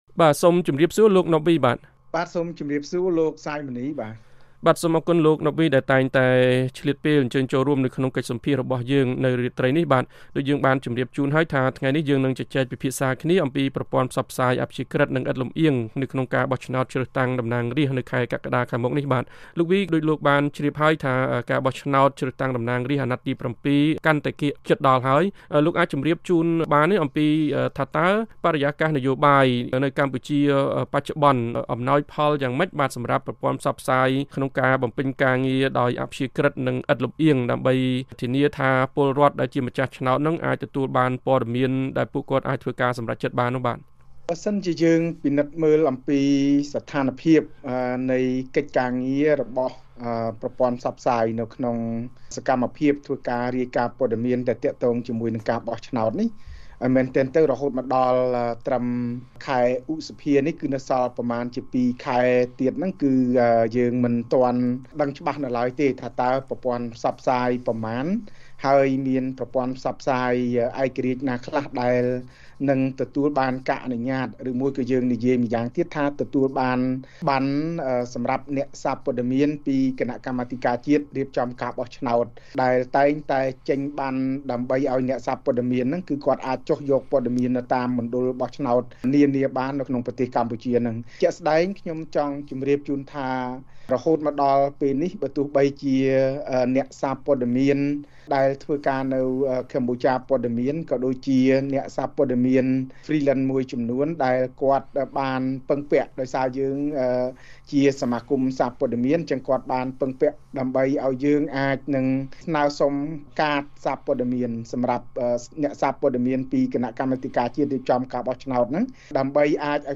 បទសម្ភាសន៍ VOA៖ ប្រព័ន្ធផ្សព្វផ្សាយឥតលម្អៀង ជំរុញការប្រកួតប្រជែងនយោបាយពិតប្រាកដដើម្បីចម្រើនជាតិ